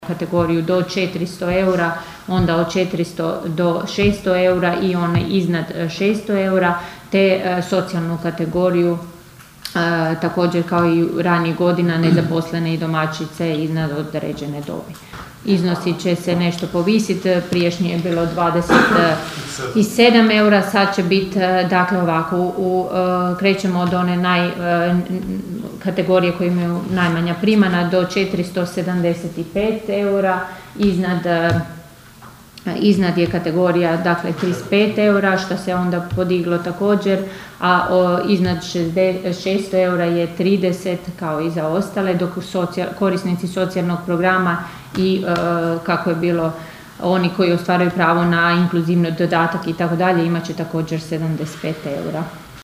Visina božićnica određena je prema unaprijed utvrđenim kategorijama korisnika, istaknula je općinska načelnica Ana Vuksan: (
ton – Ana Vuksan).